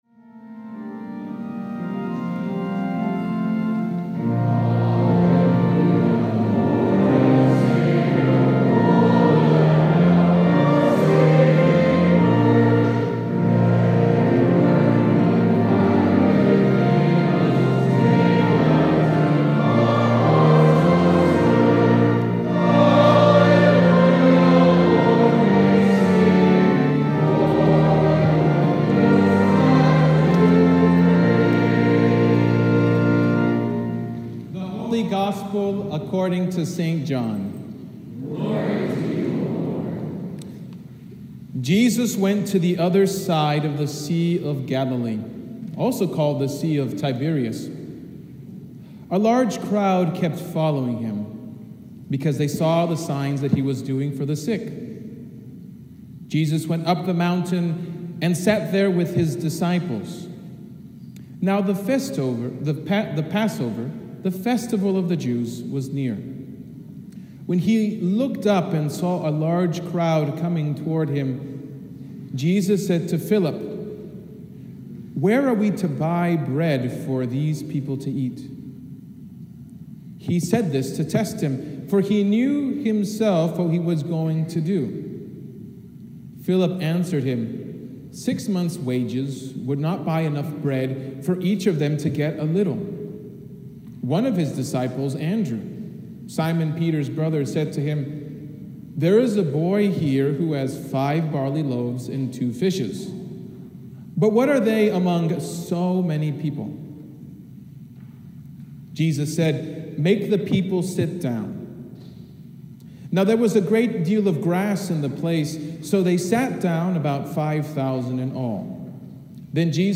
Sermon from the Tenth Sunday After Pentecost